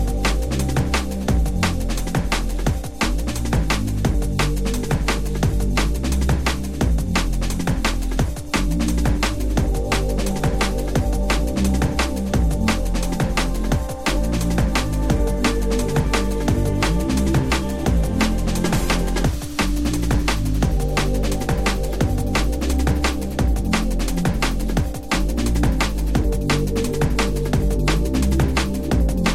TOP >Vinyl >Drum & Bass / Jungle
TOP > Deep / Liquid